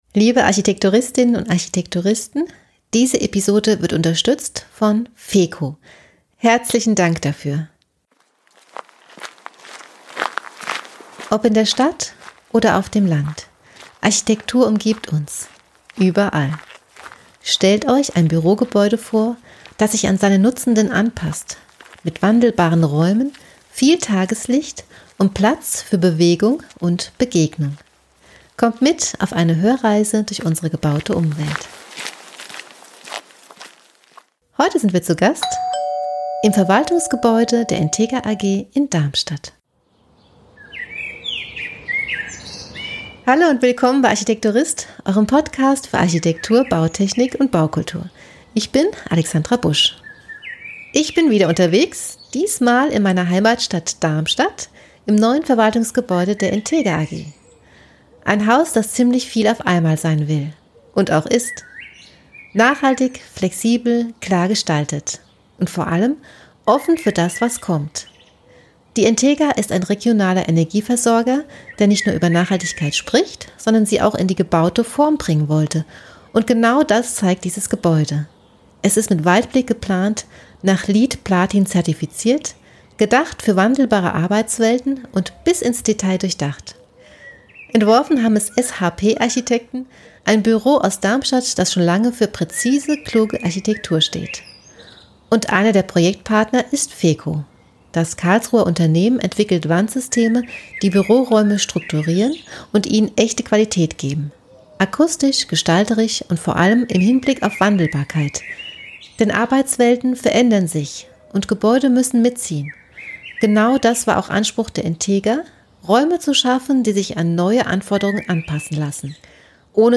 Ich nehme euch mit auf einen akustischen Rundgang durch das neue Verwaltungsgebäude und spreche mit den Menschen, die das Projekt entscheidend geprägt haben. Es geht um Stadtplanung, Materialwahl und Gestaltung, aber auch um Trennwandsysteme, die sich rückstandslos versetzen lassen und so neue Arbeitswelten ermöglichen.